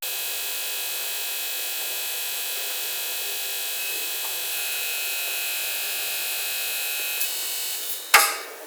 Electricidad estática de una televisión de plasma que se apaga
electricidad estática
Sonidos: Hogar